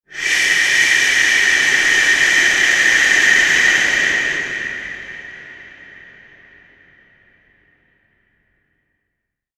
Spooky Ghost Shhh Sound Effect
Description: Spooky ghost shhh sound effect. Creepy ghostly whisper hush that adds a chilling, eerie atmosphere.
Spooky-ghost-shhh-sound-effect.mp3